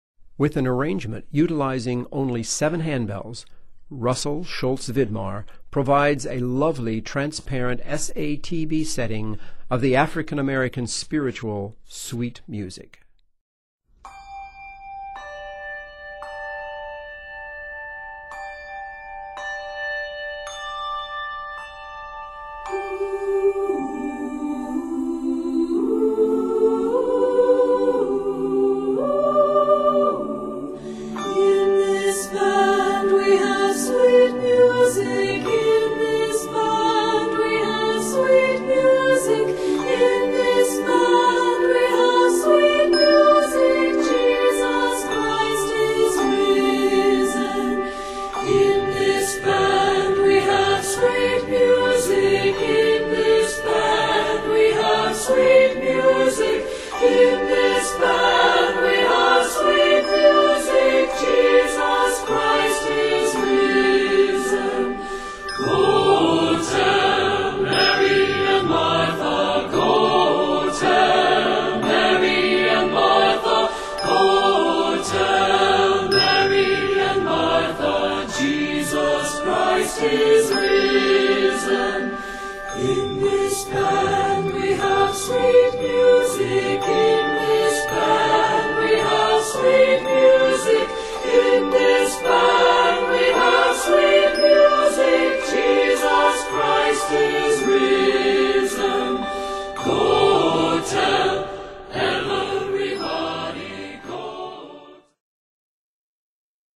African-American spirituals
Arranged in G Major and written for SATB voices